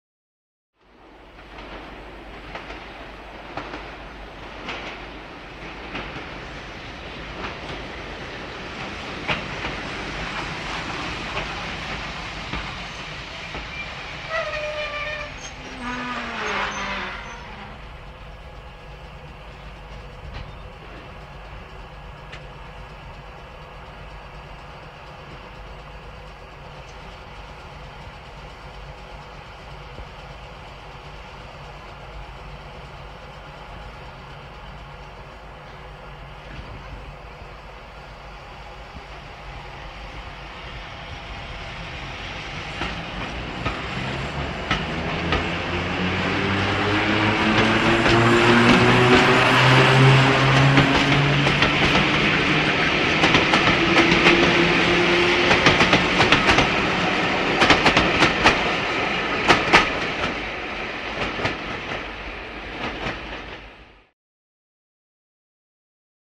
Звук электрички: прибытие на станцию, остановка и отъезд снаружи